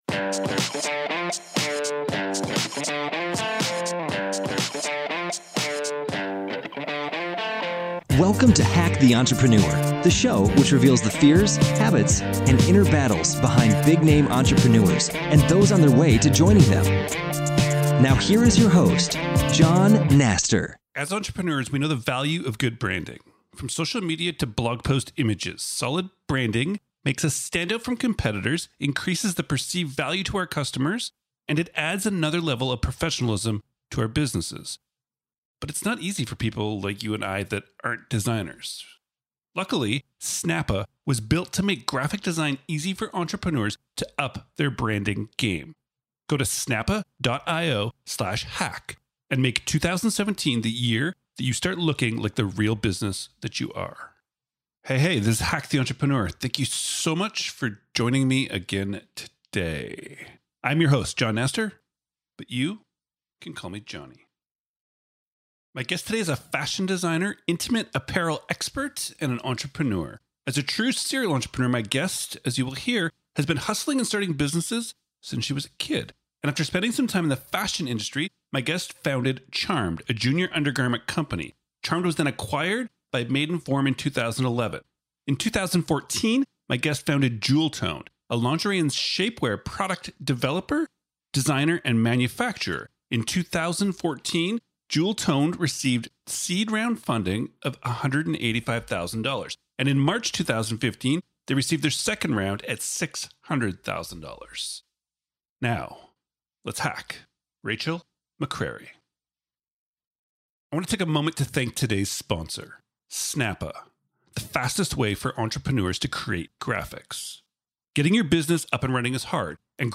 HTE 302: A Conversation About Taking Action
My guest today is a fashion designer, intimate apparel expert, and an entrepreneur.